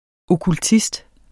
Udtale [ okulˈtisd ]